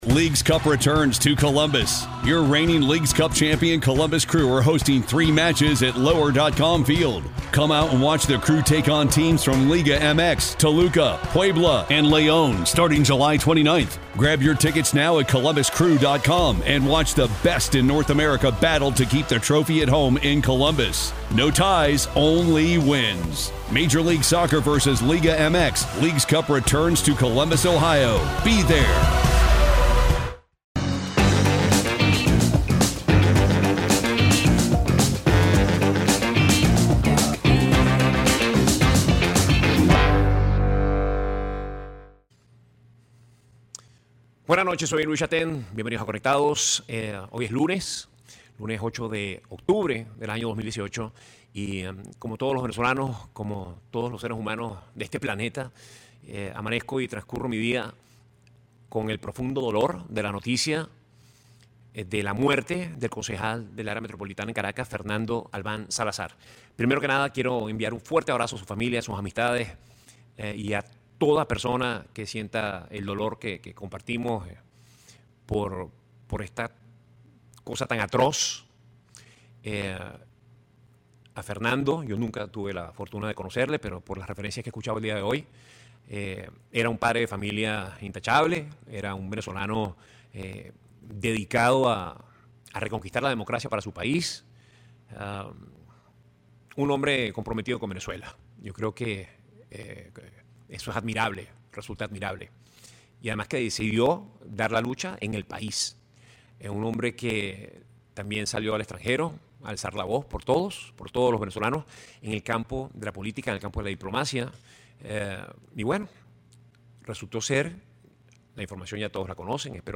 Chataing conversa con el compositor y cantante